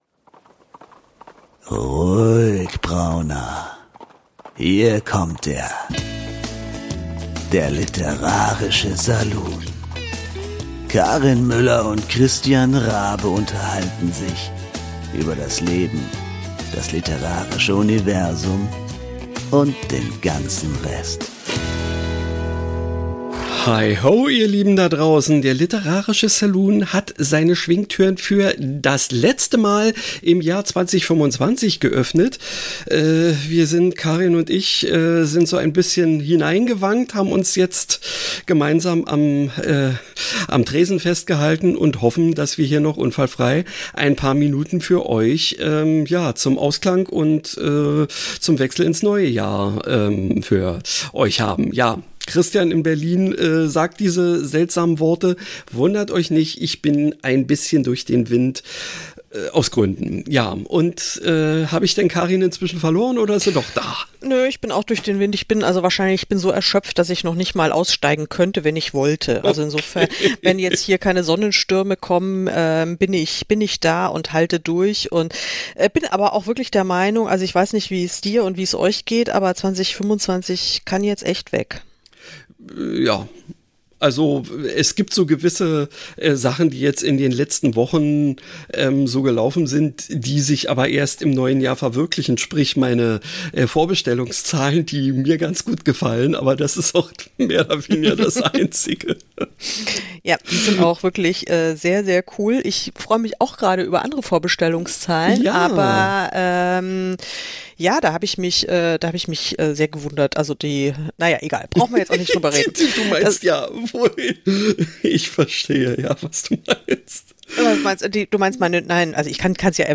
Wie war 2025 – was kommt in 2026. Mit O-Tönen von vielen Gästen.